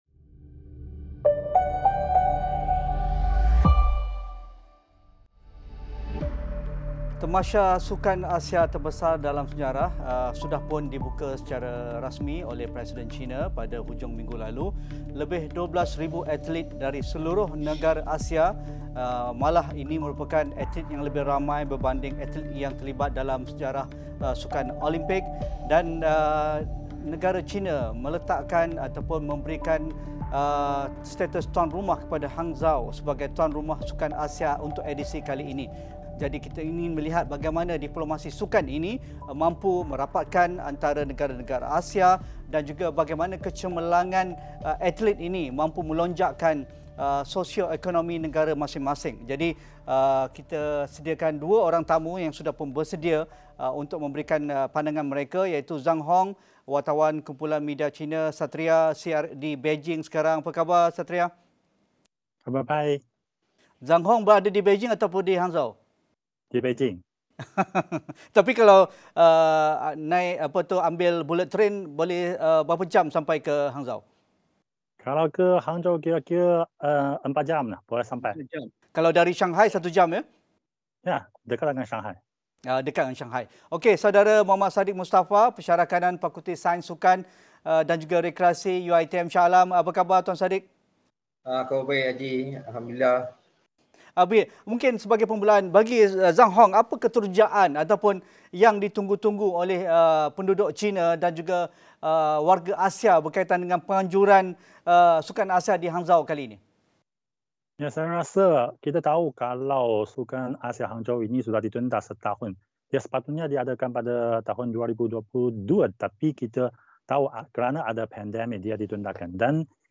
Diskusi perkembangan sukan dan atlet rantau Asia serta usaha penganjuran Sukan Asia ke-18 di Hangzhou, China dalam Agenda AWANI Asia.